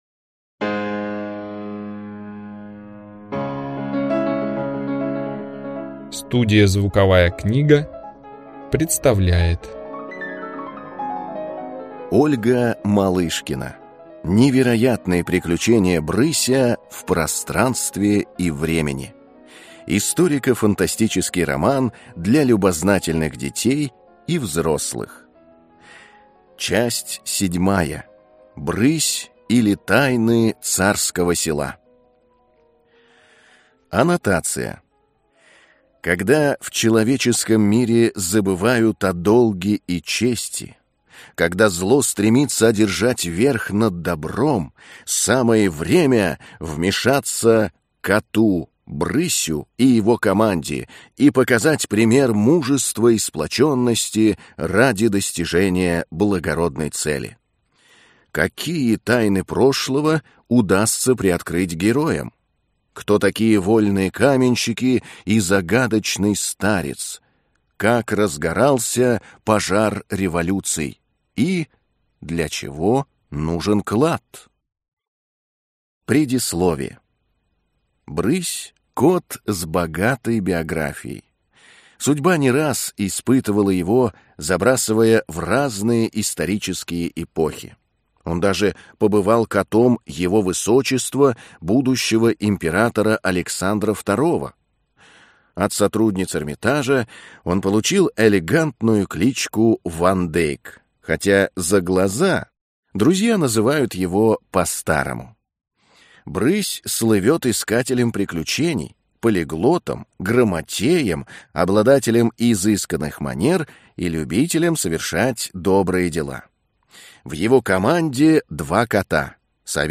Аудиокнига Книга 7. Брысь, или Тайны Царского Села | Библиотека аудиокниг